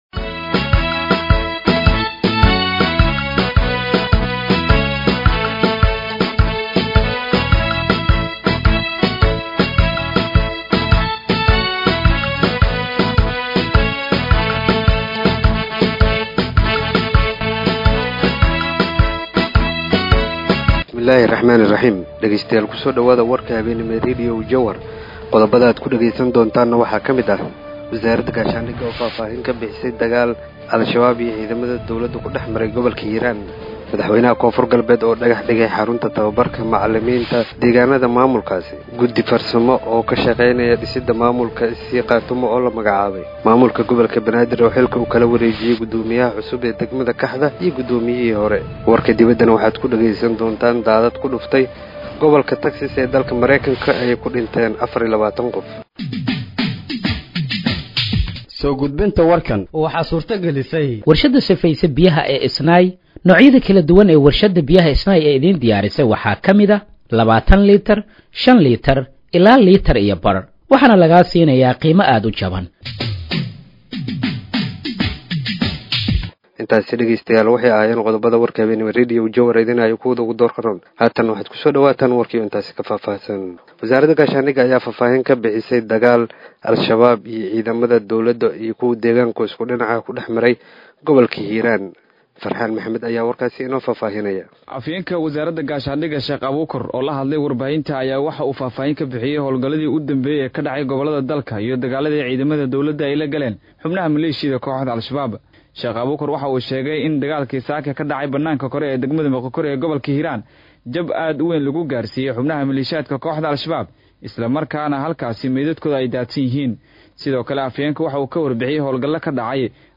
Dhageeyso Warka Habeenimo ee Radiojowhar 05/07/2025